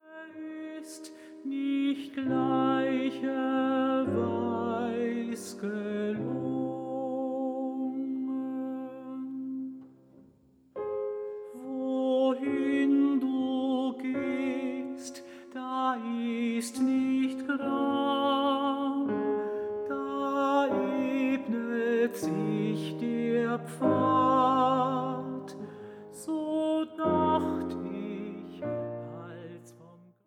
Sopran
Mezzosopran
Tenor
Bariton
Klavier